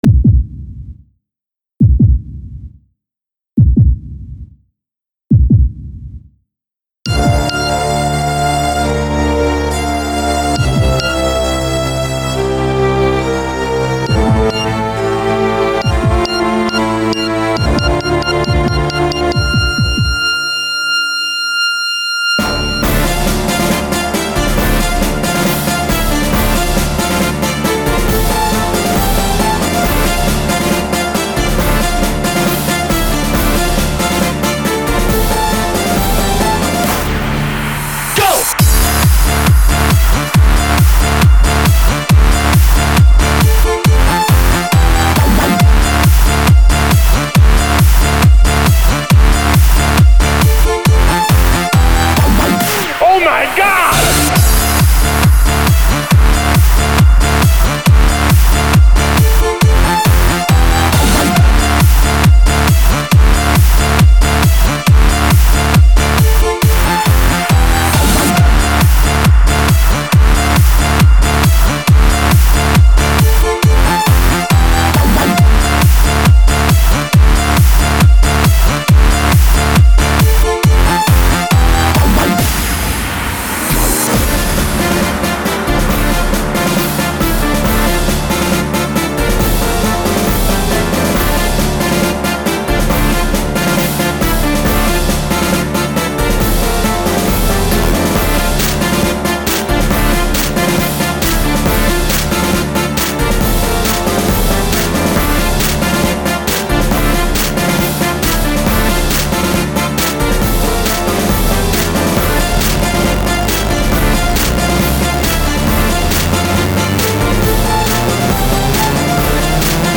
Категория: Club - Mix